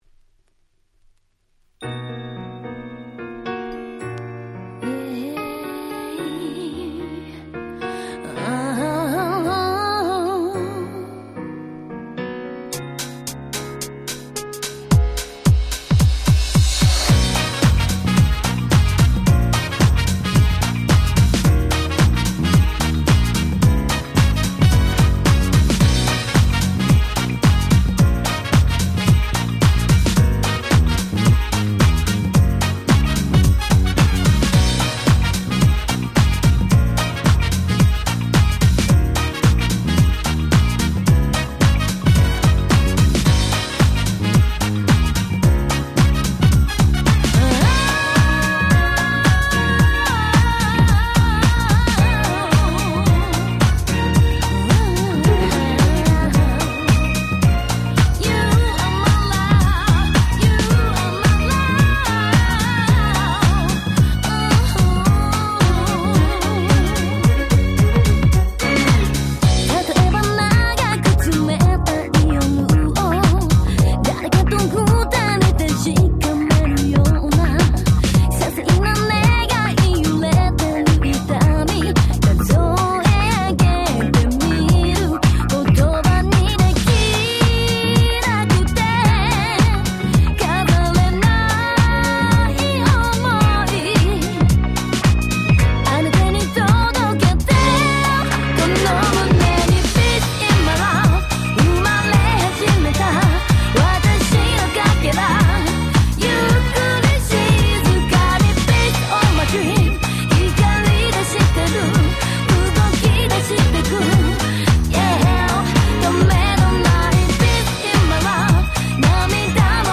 00' Nice Japanese R&B !!
踊れるBPMの爽快なダンスナンバーで超オススメ！